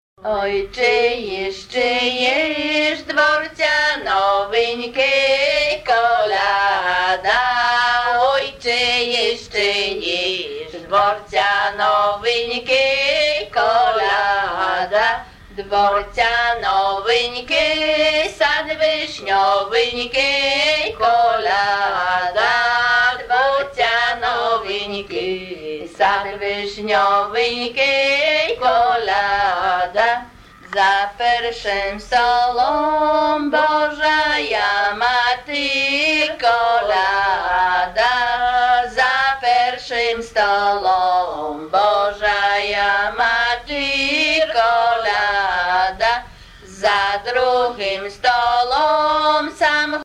Ukrainian Ethnic Music.
1 Christmas Carol Play